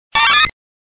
Cri de Pichu dans Pokémon Diamant et Perle.